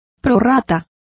Complete with pronunciation of the translation of prorates.